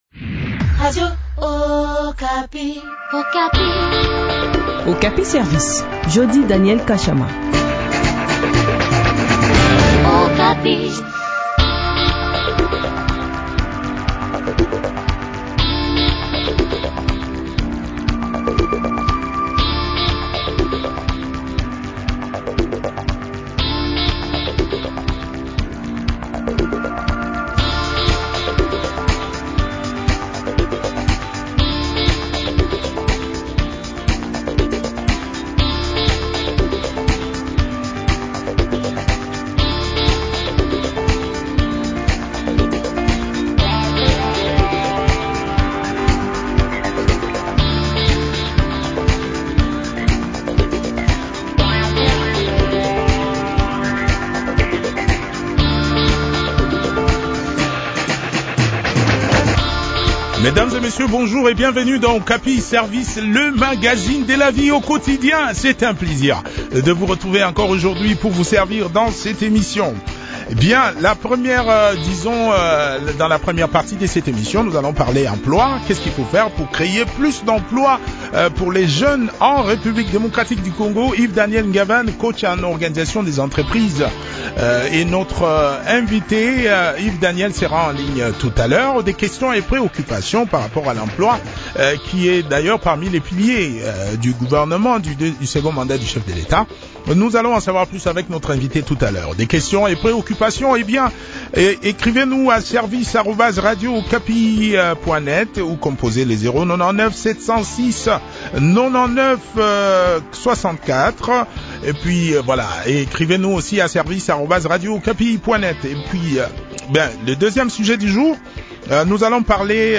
coach en organisation des entreprises.